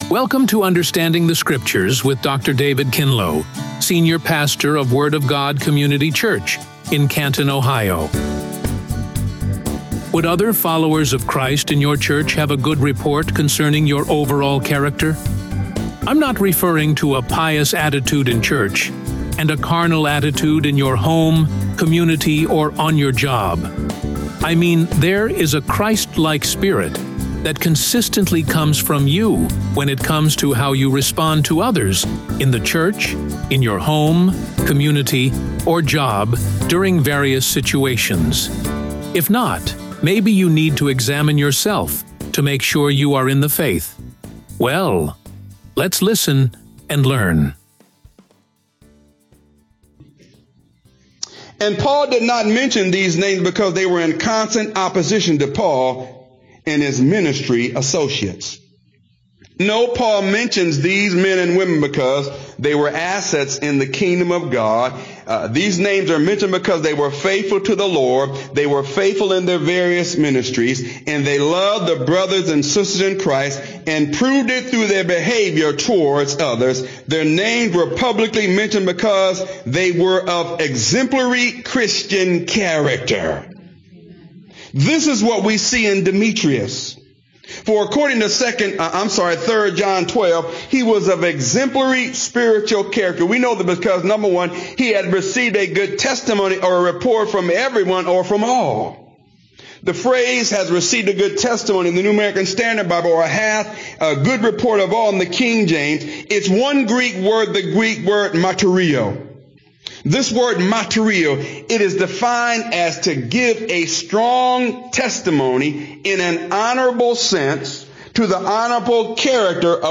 Radio Sermons | The Word of God Community Church